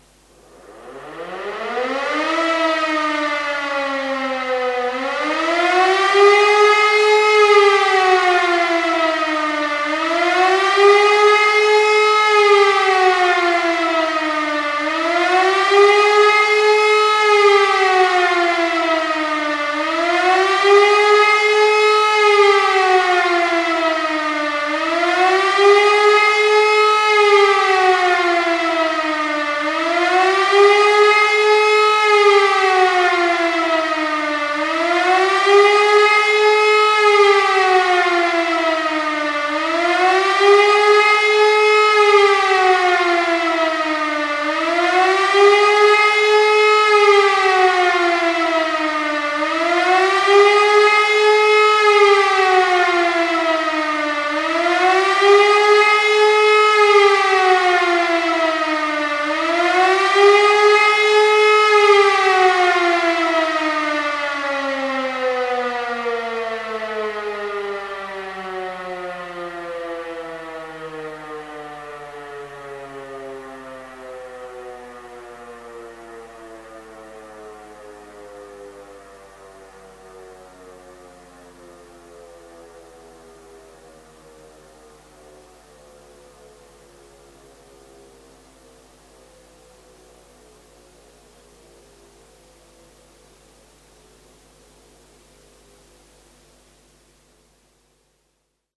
Luftalarm (Fliegeralarm)
Der Fliegeralarm aus dem Zweiten Weltkrieg wurde zum Luftalarm, der bis Anfang der 1990er Jahre mit dem 1 Minute Heulton die Bevölkerung im Verteidigungsfall vor Luftangriffen hätte warnen sollen.
sirenensiegnal-luftalarm.mp3